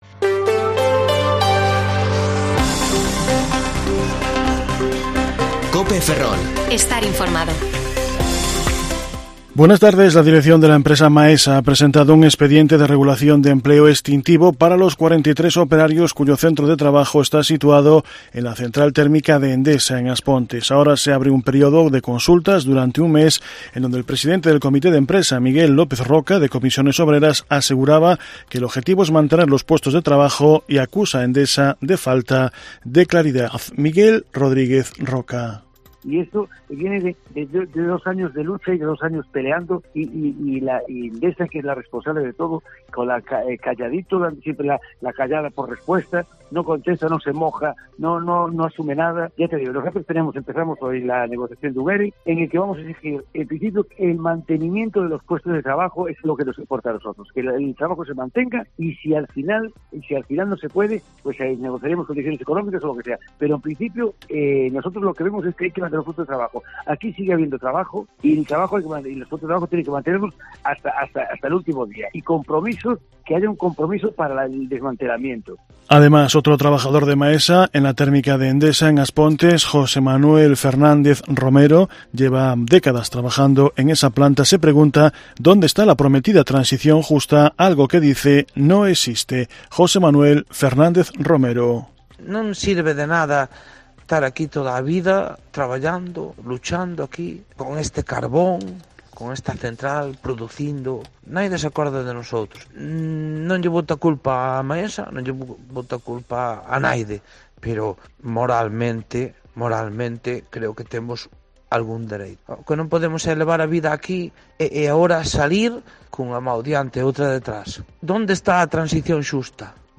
Informativo Mediodía COPE Ferrol 2/9/2021 (De 14,20 a 14,30 horas)